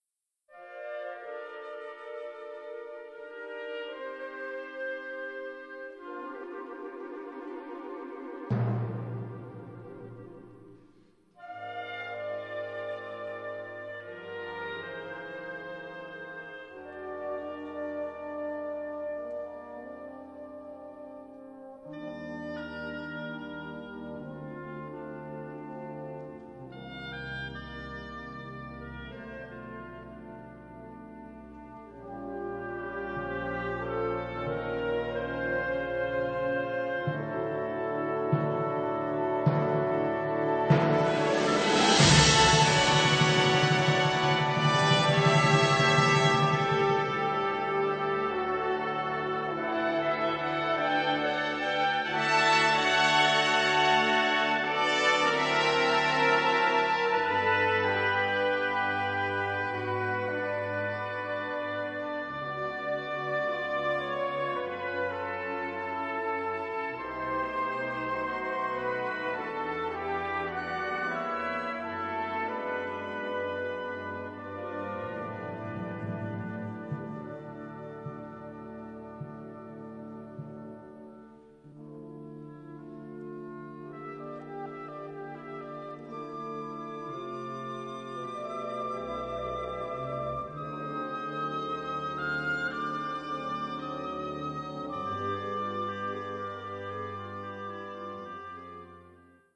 Catégorie Harmonie/Fanfare/Brass-band
Sous-catégorie Musique contemporaine (1945-présent)
Instrumentation Ha (orchestre d'harmonie)